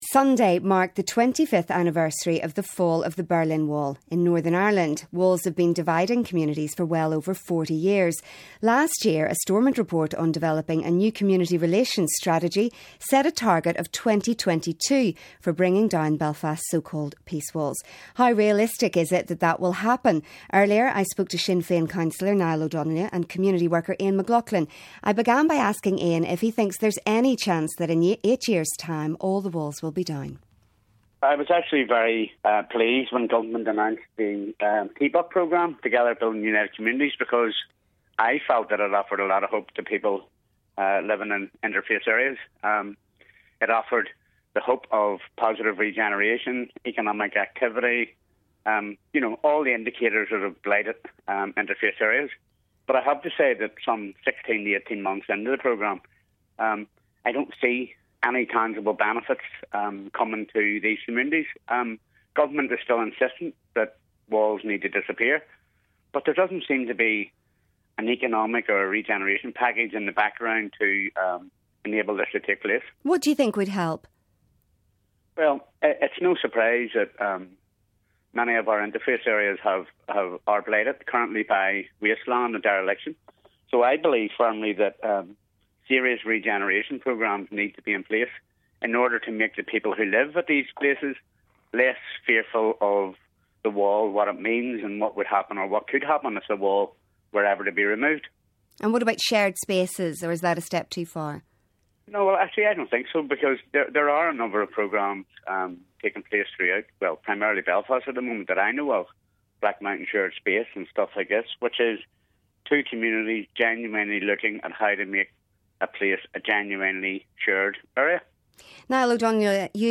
community worker